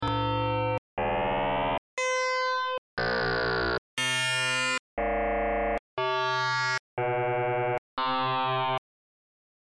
How to hear FM Synthesis
This demo contains some examples of sounds that I made by just playing around with the parameters:
All sounds were generated using csound.